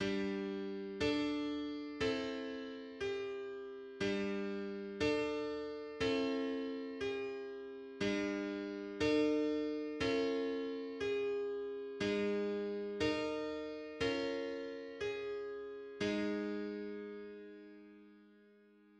Comma pump
Giovanni Benedetti's 1563 example of a comma "pump" or drift by a comma during a progression.[5]
Play Common tones between chords are the same pitch, with the other notes tuned in pure intervals to the common tones.